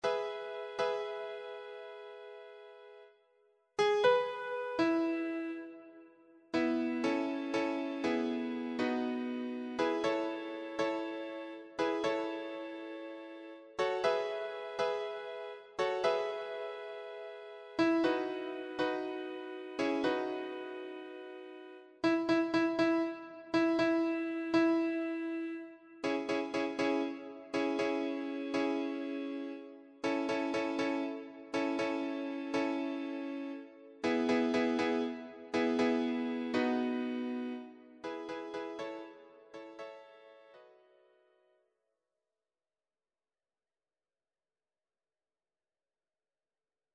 choir SAA
a relentlessly syncopated, joyful song
Electronically Generated
Sibelius file